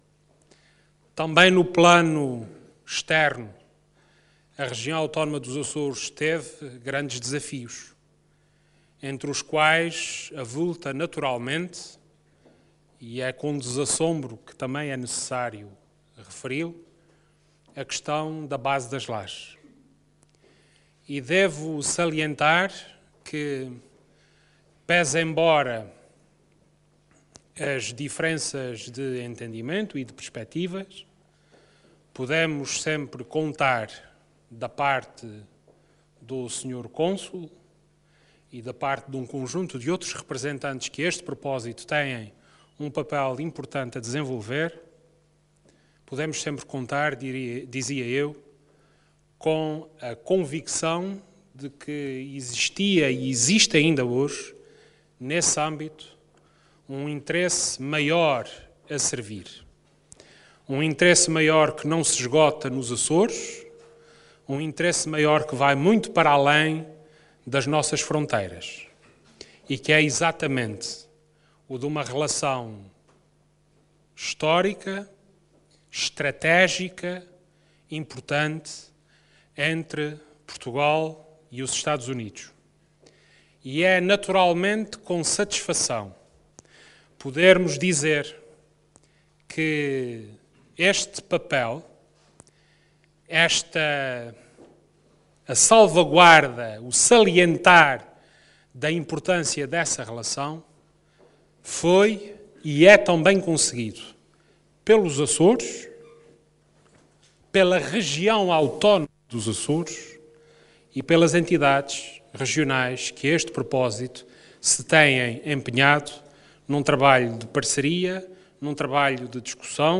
O Presidente do Governo falava segunda-feira na Receção de Ano Novo aos representantes consulares e às autoridades civis, militares e religiosas dos Açores, que tradicionalmente decorre no Palácio de Sant´Ana, em Ponta Delgada, no Dia de Reis.